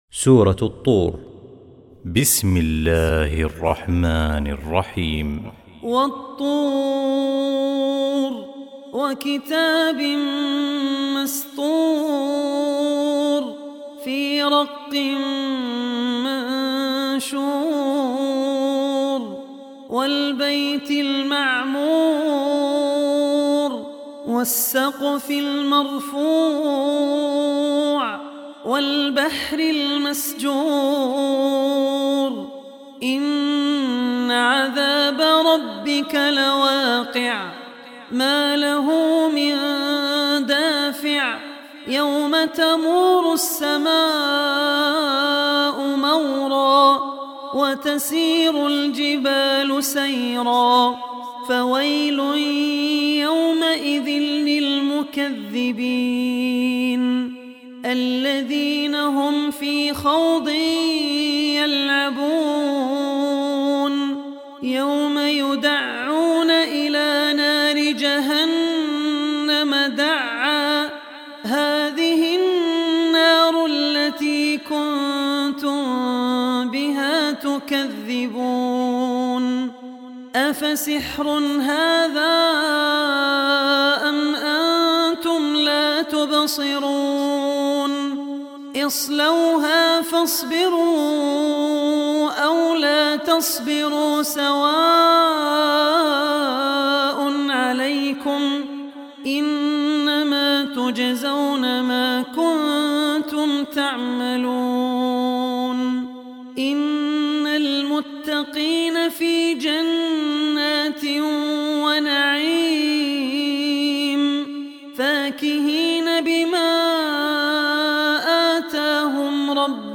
Surah At-Tur Recitation by Abdur Rehman al Ossi
Surah At-Tur, listen online mp3 tilawat / recitation in the beautiful voice of Sheikh Abdur Rehman Al Ossi.